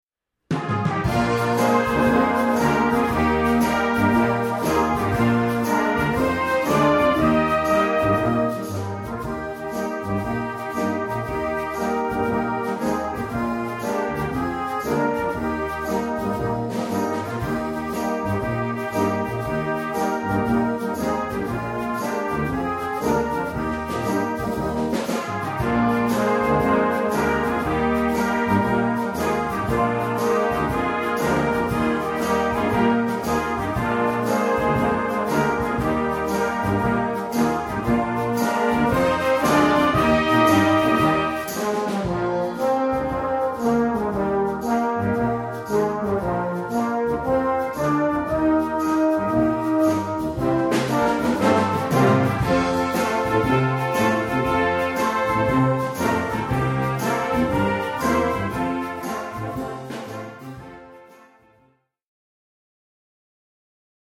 2:40 Minuten Besetzung: Blasorchester PDF